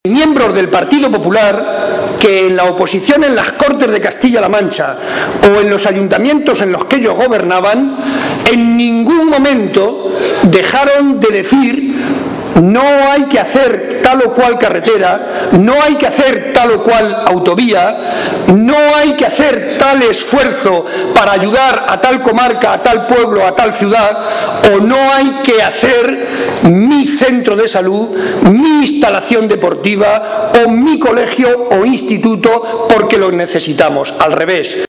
Barreda hacía estas declaraciones en el transcurso del Comité Provincial Extraordinario del PSOE de Ciudad Real donde ha sido ratificado como candidato número 1 al Congreso de los Diputados, un foro en el que ha denunciado la “campaña bestial” a la que está siendo sometido por parte de Cospedal y el resto de dirigentes del PP a base de “infamias, graves insultos y calumnias”.
Cortes de audio de la rueda de prensa